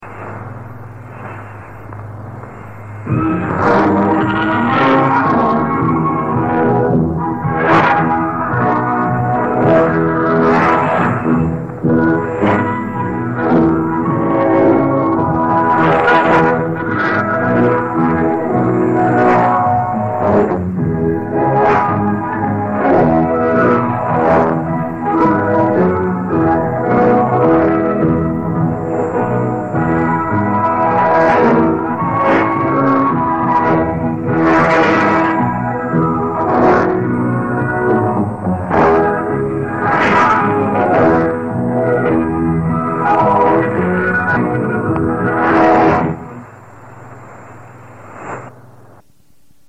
短波放送の録音をMP3でアップしました。